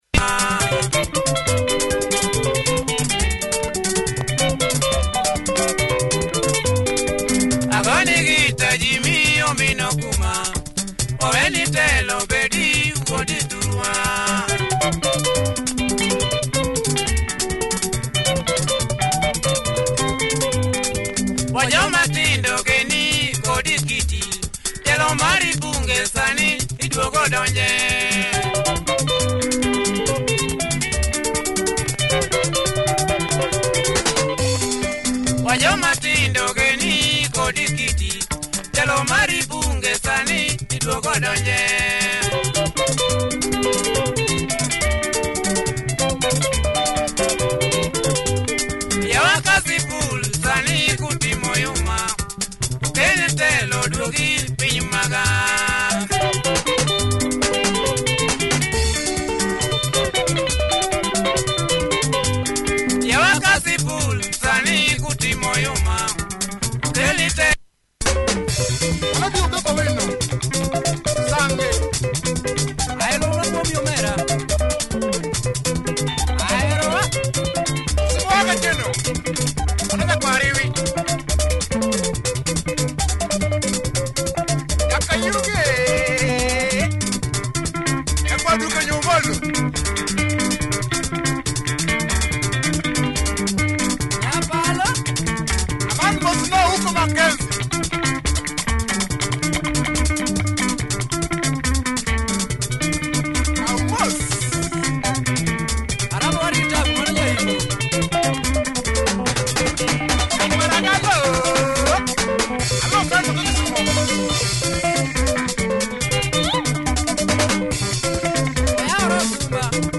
Cool LUO benga